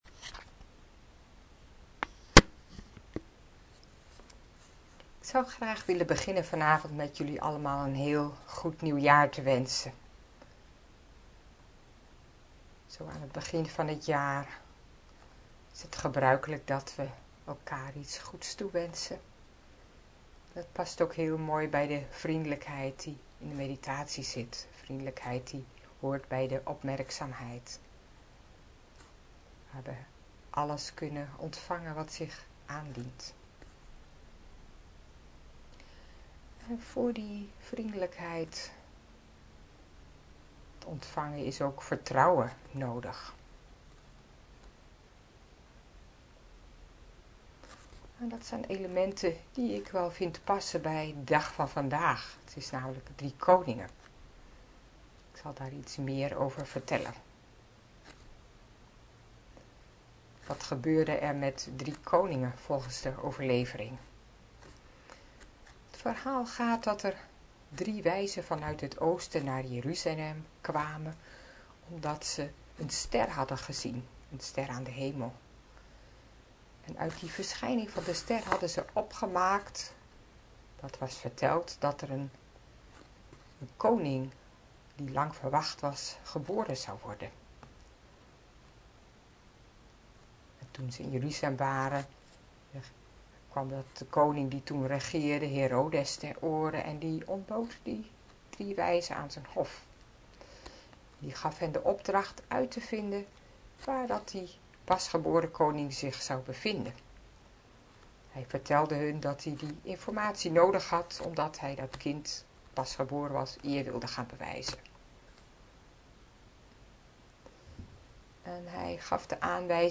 Lezing “Driekoningen”